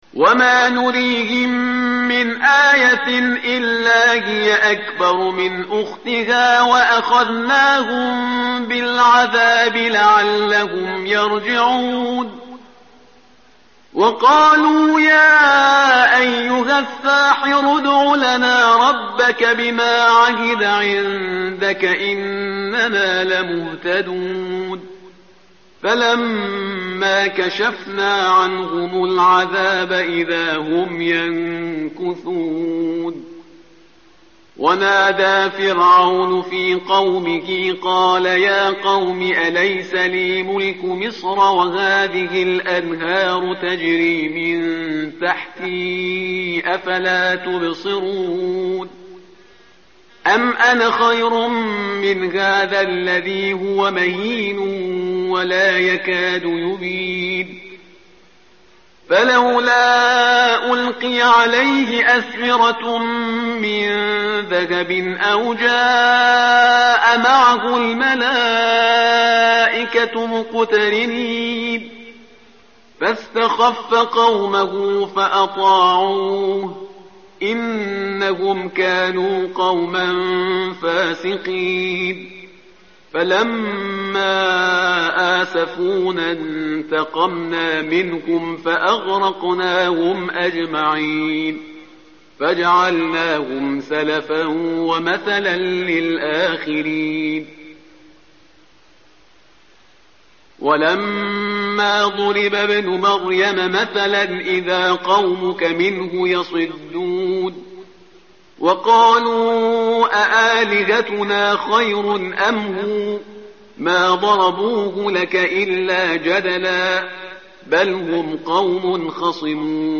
متن قرآن همراه باتلاوت قرآن و ترجمه
tartil_parhizgar_page_493.mp3